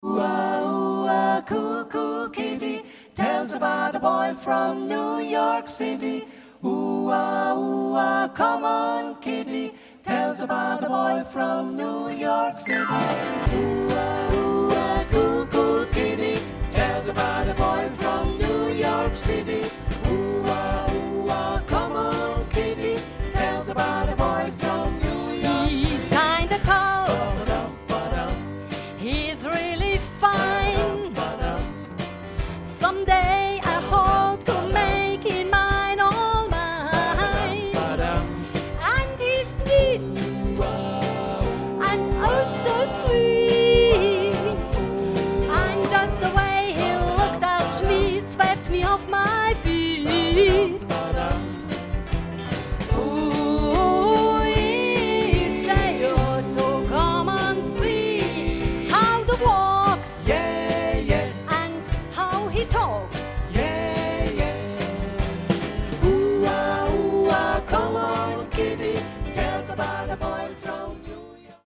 Wohlen, Sternensaal